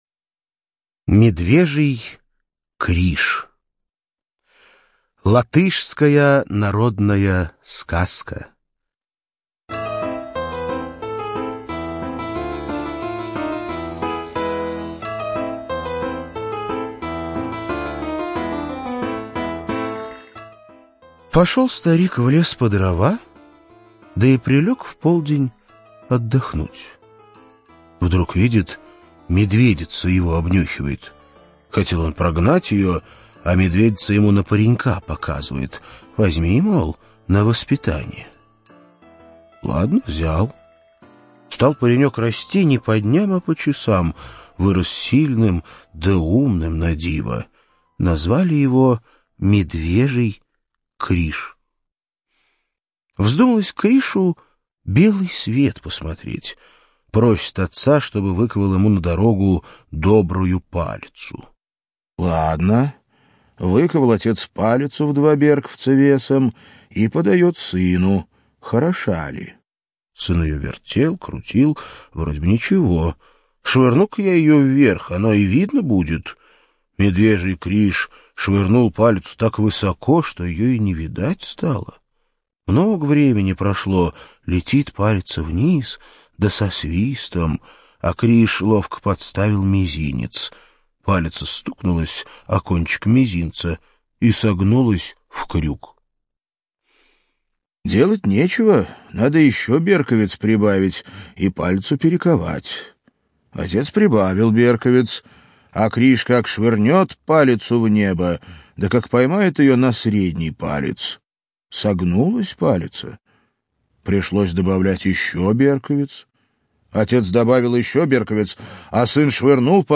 Медвежий Криш - латышская аудиосказка - слушать онлайн